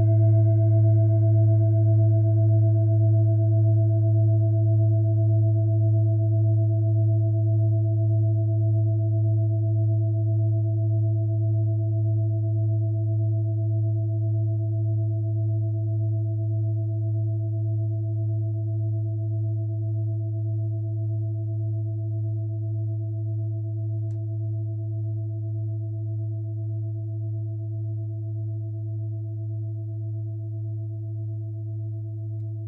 Tibet Klangschale Nr.33, Planetentonschale: Limbus
Klangschalen-Durchmesser: 28,8cm
(Ermittelt mit dem Filzklöppel)
Die Klangschale hat bei 107.66 Hz einen Teilton mit einer
Die Klangschale hat bei 330.32 Hz einen Teilton mit einer
Die Klangschale hat bei 340.82 Hz einen Teilton mit einer
klangschale-tibet-33.wav